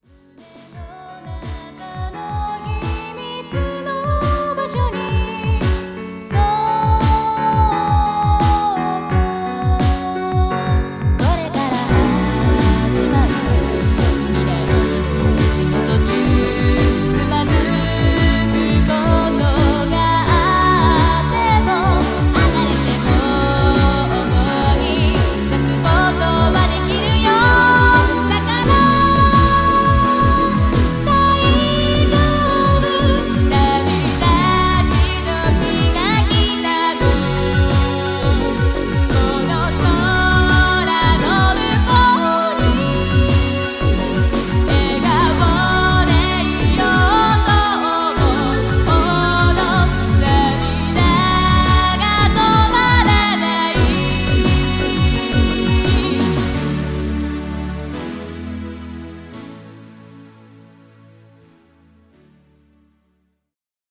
オケも本作頒布にあたって新規に収録しなおしました。